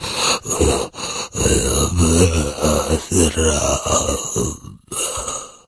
fracture_die_1.ogg